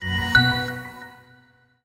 PowerOff.ogg